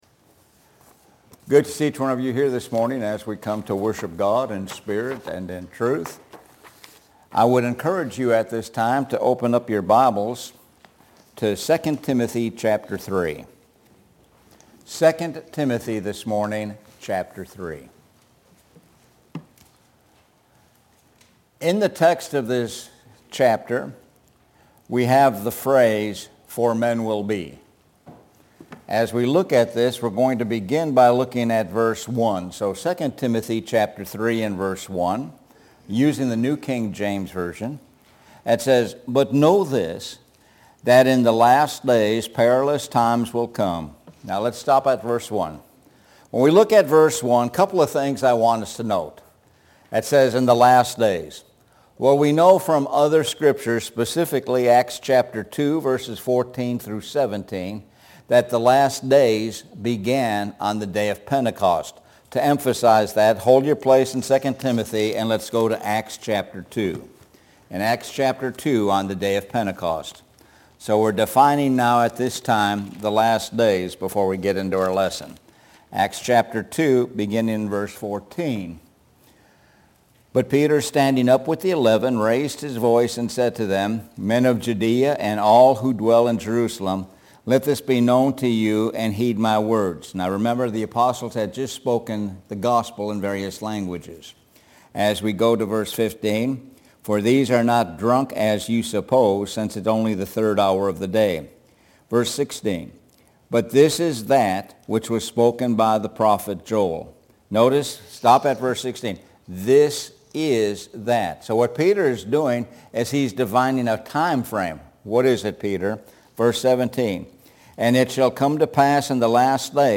Sun AM Sermon